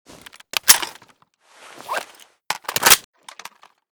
fal_reload.ogg